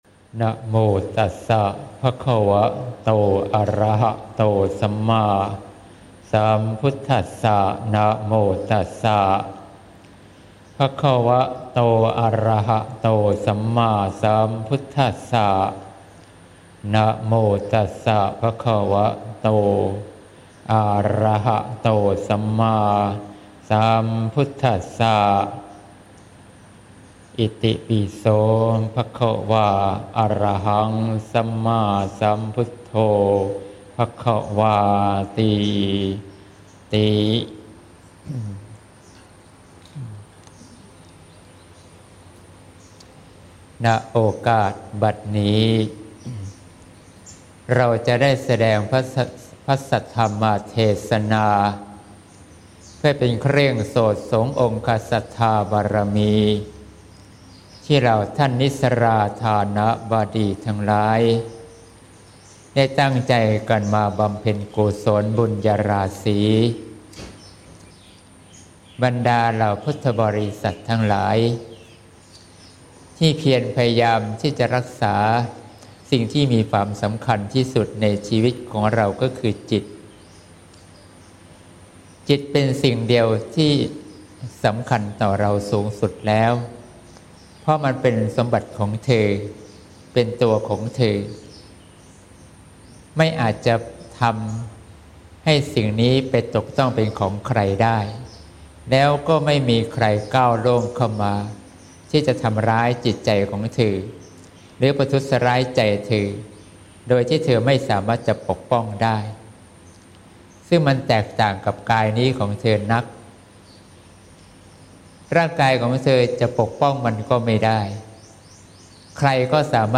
เทศน์ (เสียงธรรม ๒๘ มิ.ย. ๖๘)